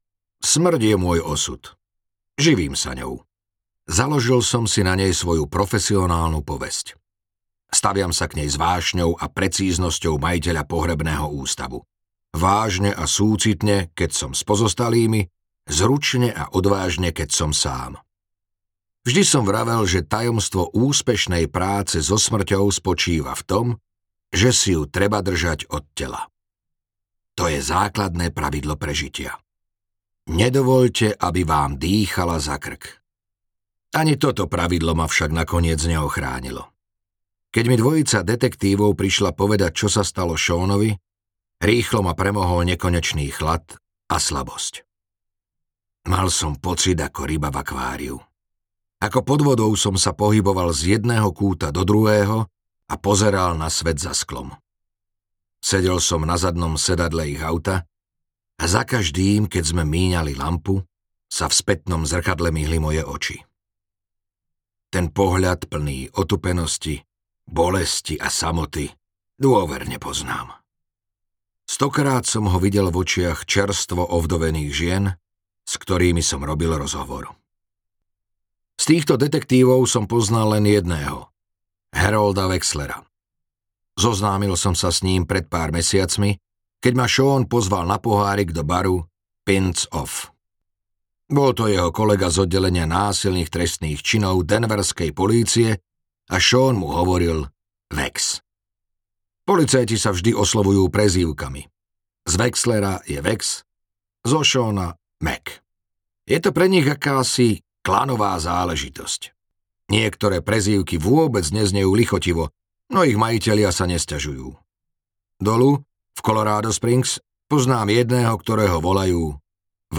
Básnik smrti audiokniha
Ukázka z knihy